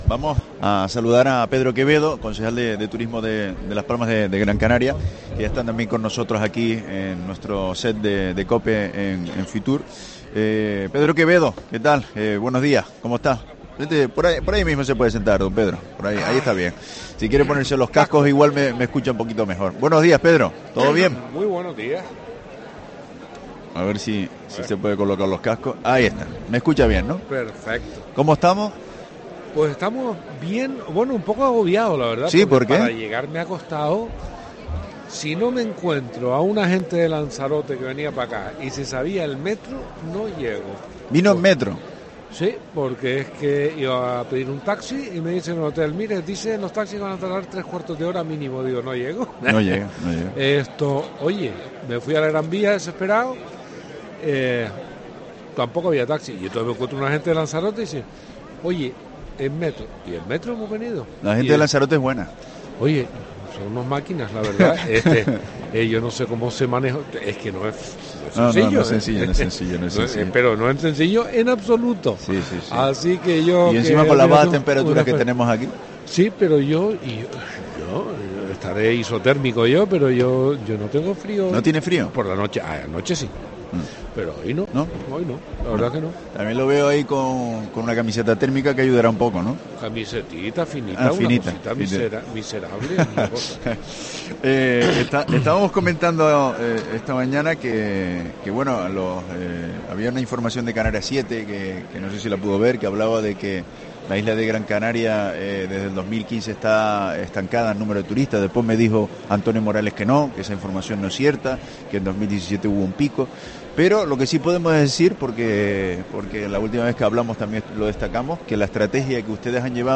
Entrevista a Pedro Quevedo, concejal de Turismo de Las Palmas de Gran Canaria, en La Mañana de COPE en FITUR